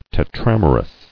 [te·tram·er·ous]